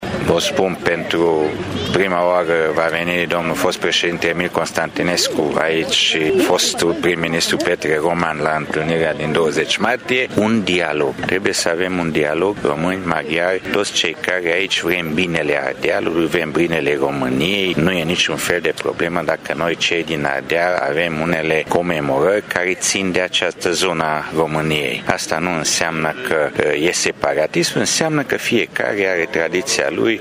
Este declarația vicepreședintelui politic al UDMR, Borbely Laszlo, prezent astăzi la Tîrgu-Mureș la manifestările prilejuite de Ziua Maghiarilor de Pretutindeni.